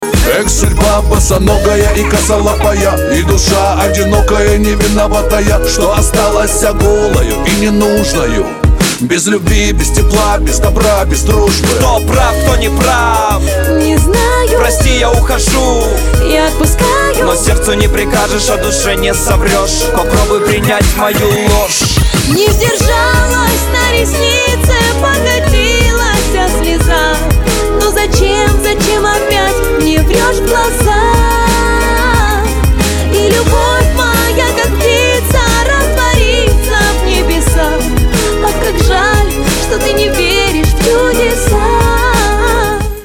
• Качество: 256, Stereo
лирика